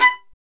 Index of /90_sSampleCDs/Roland - String Master Series/STR_Violin 1-3vb/STR_Vln2 % marc